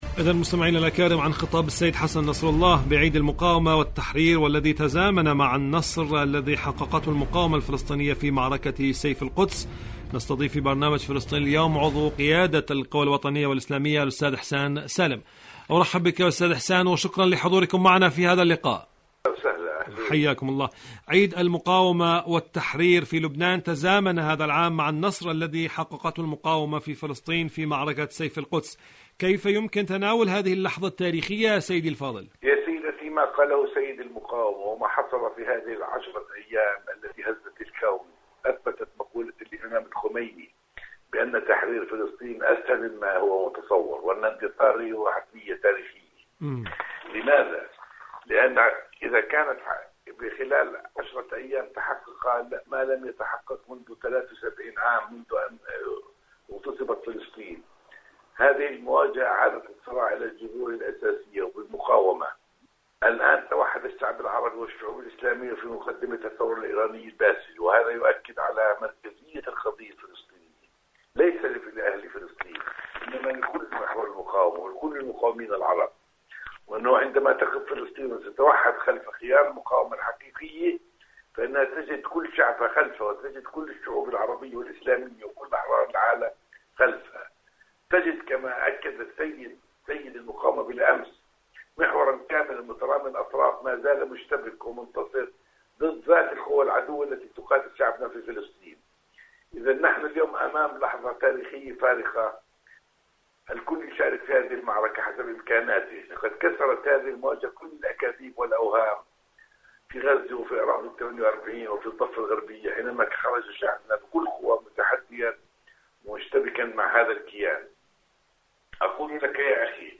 مقابلات برامج إذاعة طهران برنامج فلسطين اليوم القدس الشريف المسجد الأقصى كيان الاحتلال مقابلات إذاعية قاوم تنتصر اليوم لبنان وغدا فلسطين شاركوا هذا الخبر مع أصدقائكم ذات صلة الردع الإيراني والمقاومة الفلسطينية..